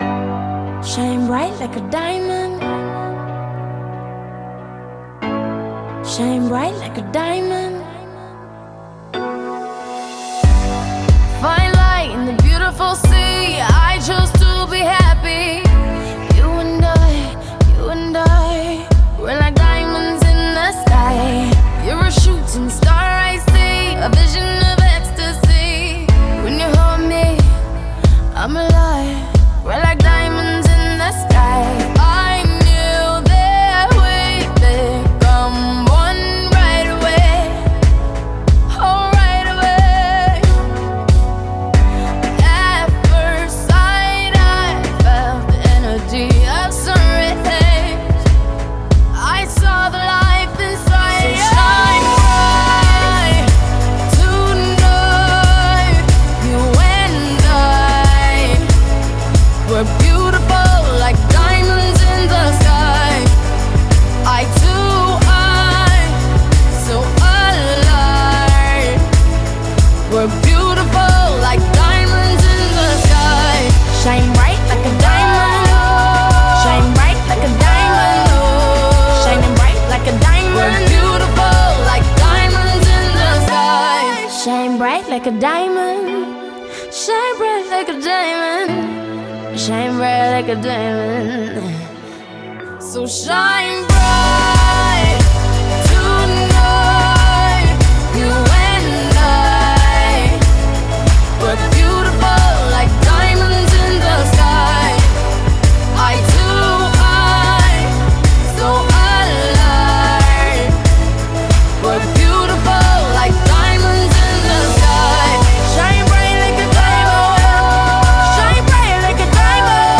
BPM92
Audio QualityMusic Cut